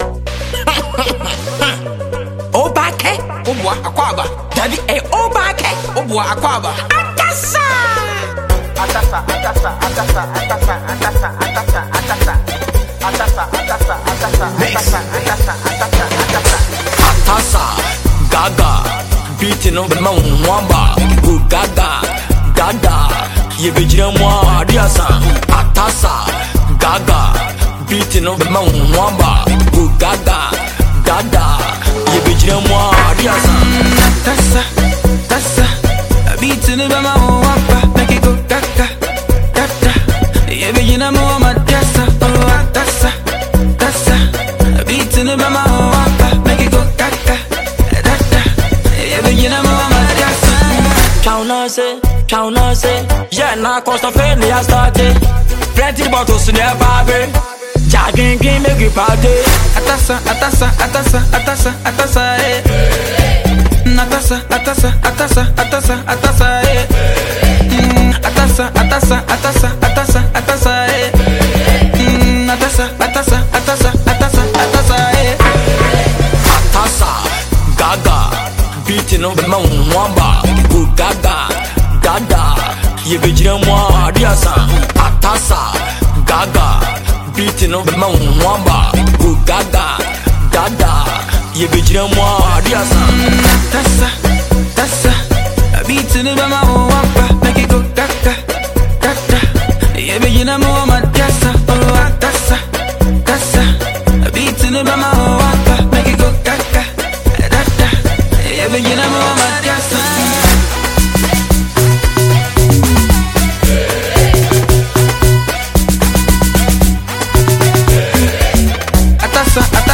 With catchy hooks and rhythmic beats